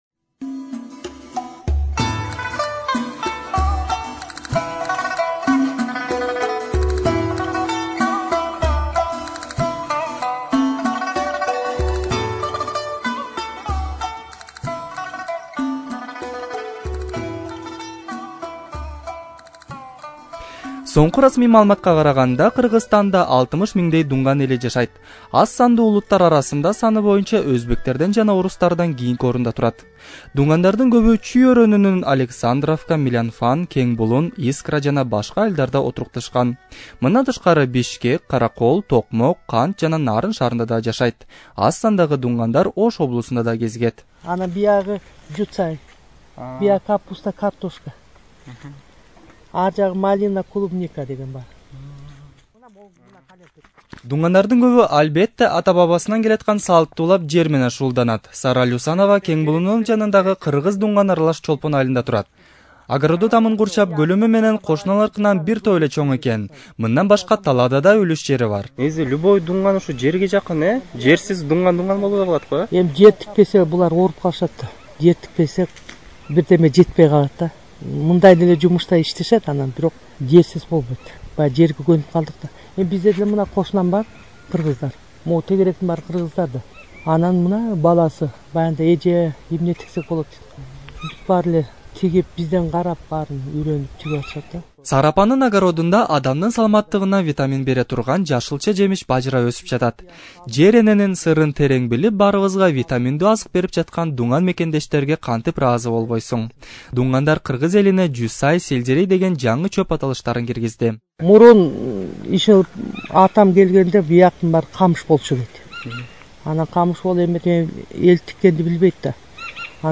Kyrgyzstan: Radioreport about Dungan people in Kyrgyzstan